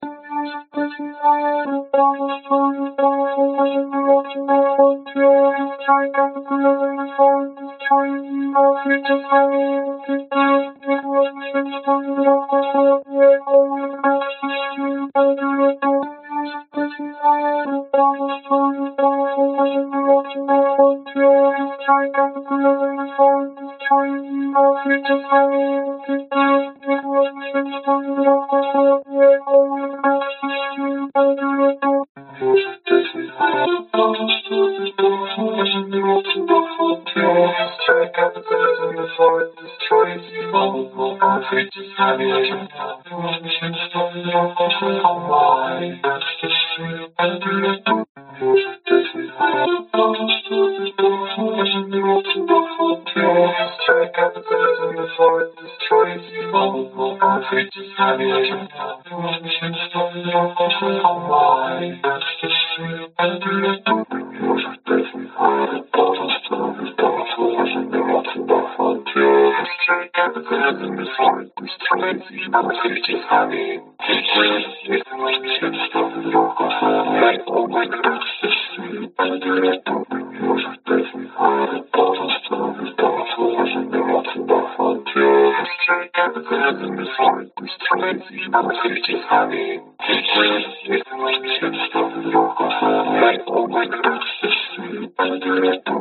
声码器声音
描述：声码器实验。 录制了一些声音，用声码器做了一些编辑（TAL声码器）。
Tag: 男声 女声 电子 实验 循环 口语 电子 人声 技术 声码器 实验